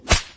绳子抽打.mp3